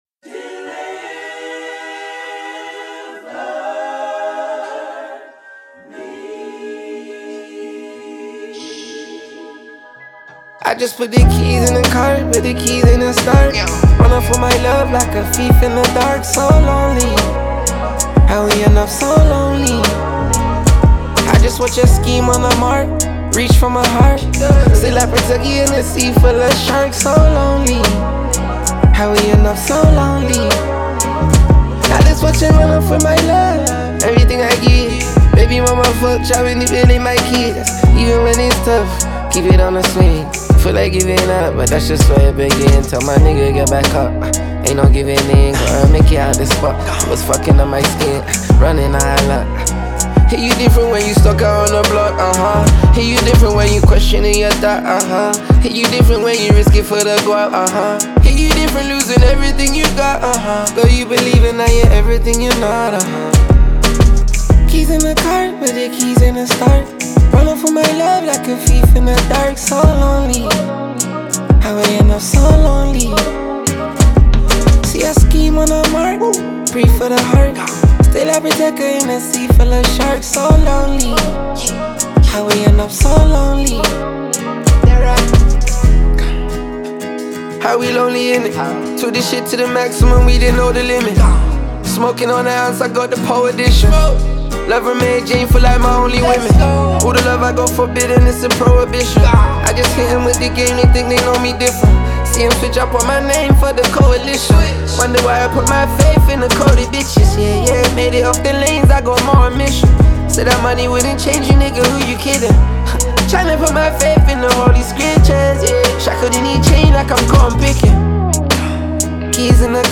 Грустные
Трек размещён в разделе Рэп и хип-хоп / Зарубежная музыка.